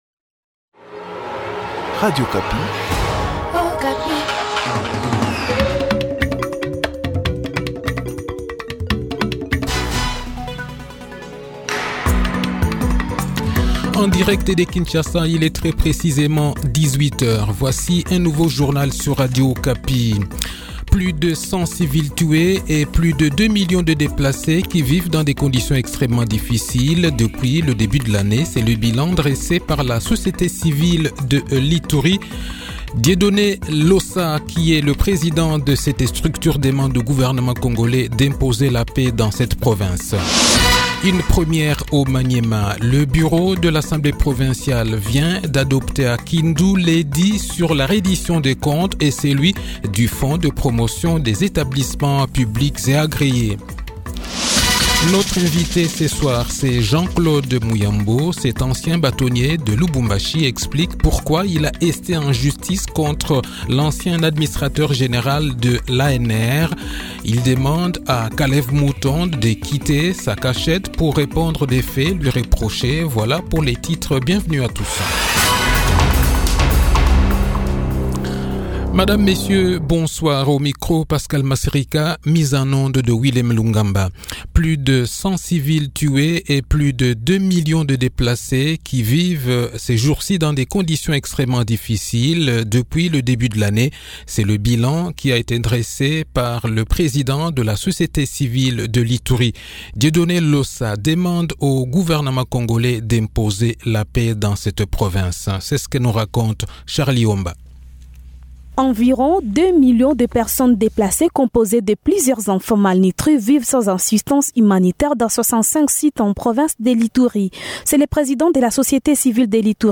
Le journal de 18 h, 10 Avril 2021